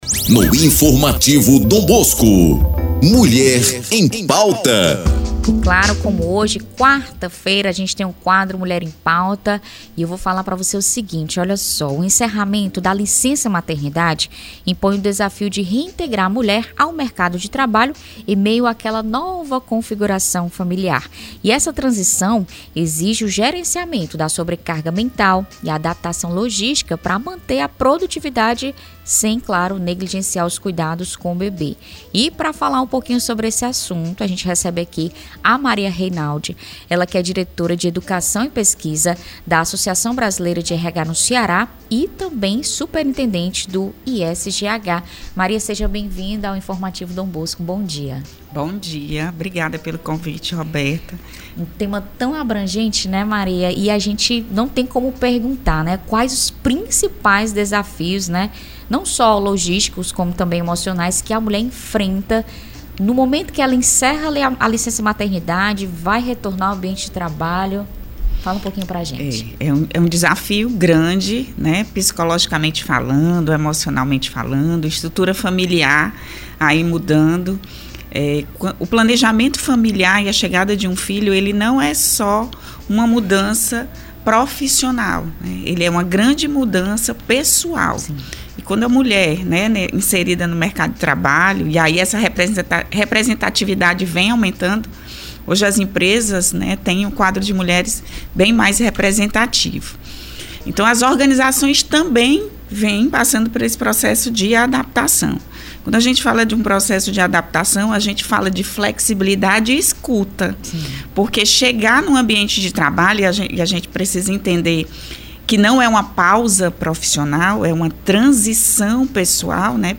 Retorno ao trabalho após a licença-maternidade exige adaptação e rede de apoio; confira entrevista